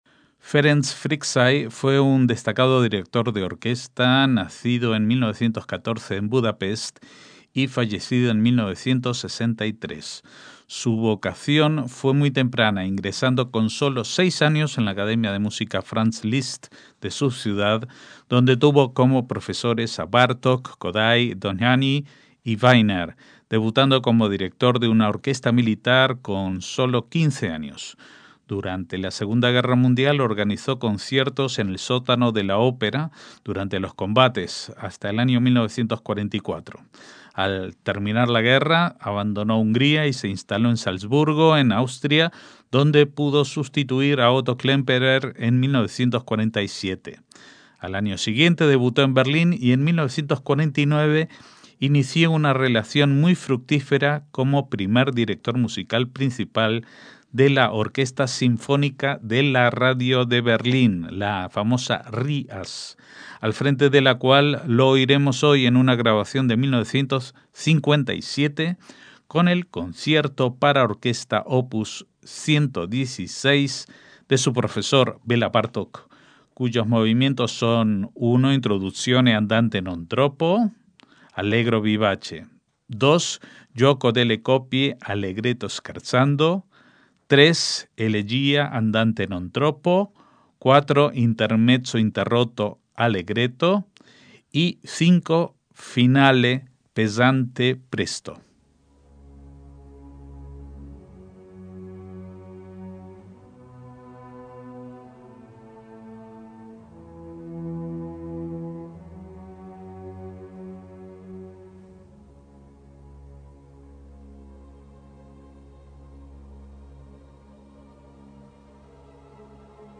MÚSICA CLÁSICA - Ferenc Fricsay fue un destacado director de orquesta nacido en 1914 en Budapest y fallecido en 1963.